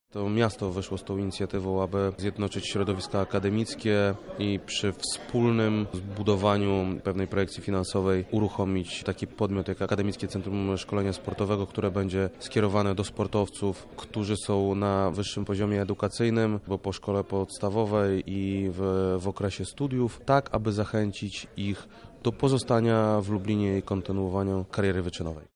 – Lublin chce stworzyć młodym sportowcom warunki rozwoju- podkreśla zastępca prezydenta miasta Krzysztof Komorski